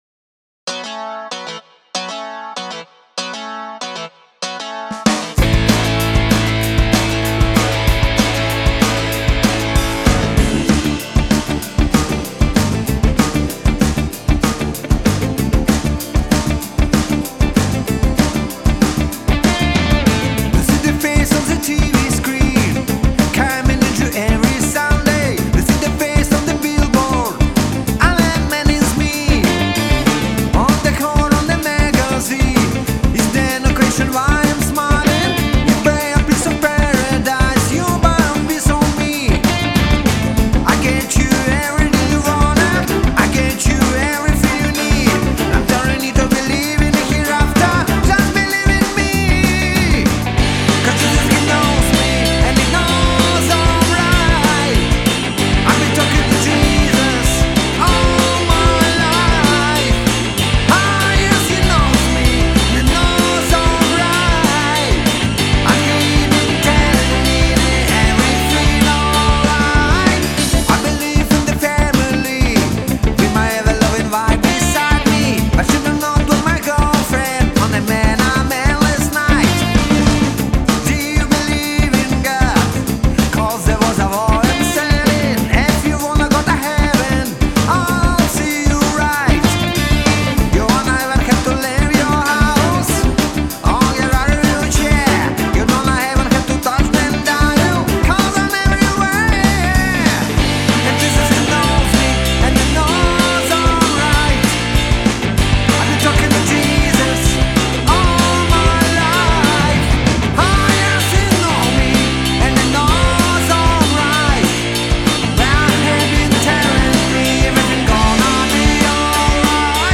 я в бэках участвую..ты права!